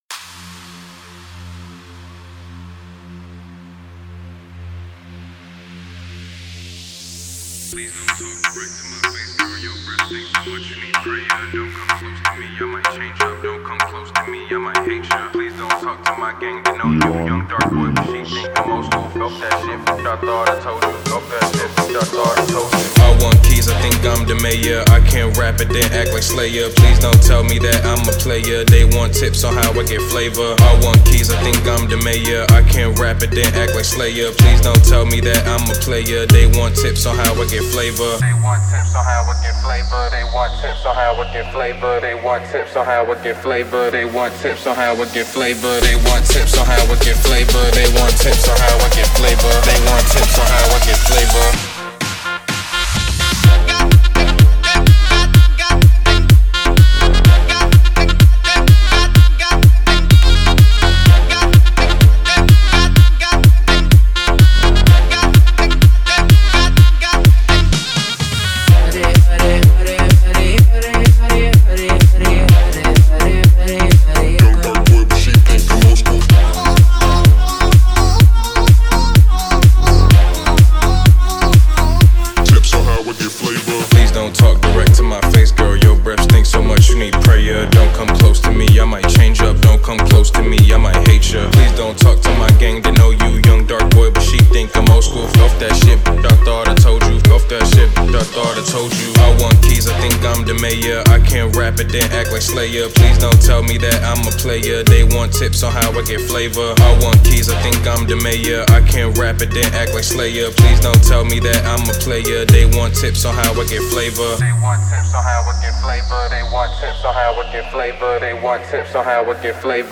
Download remix house for meeting